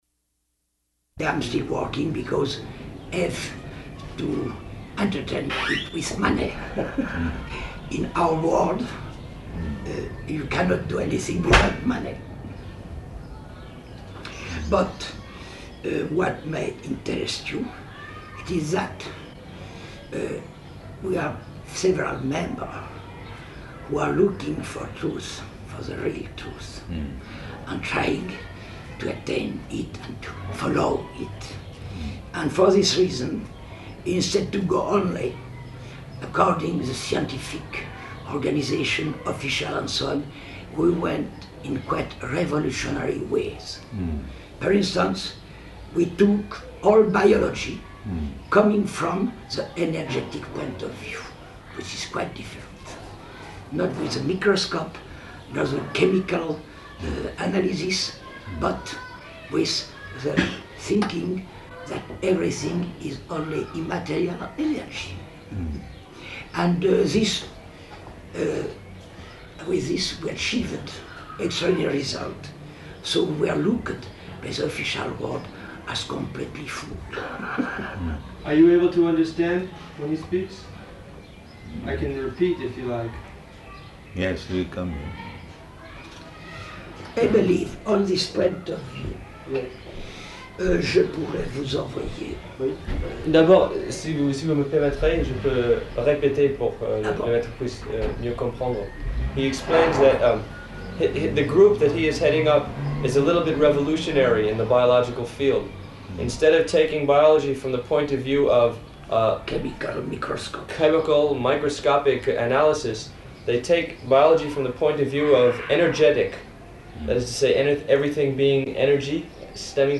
-- Type: Conversation Dated: June 4th 1974 Location: Geneva Audio file